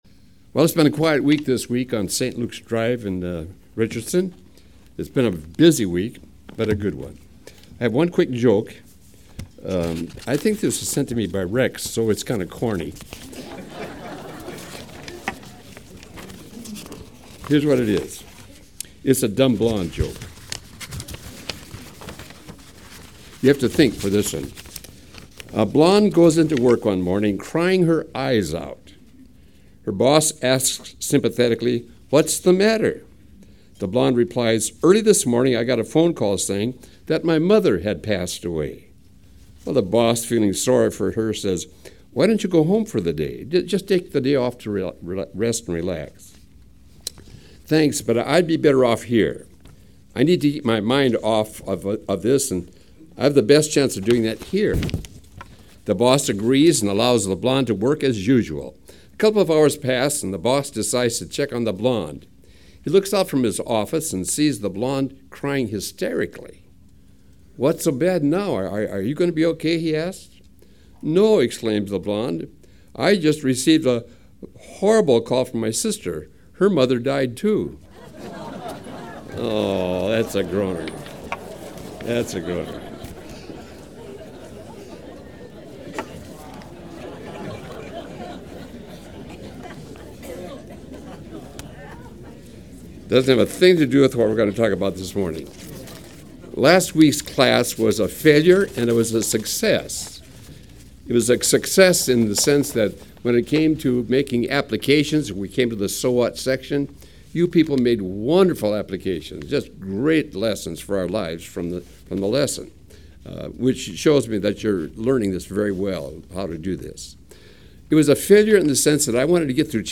Acts Lesson 29: Victory and Vengeance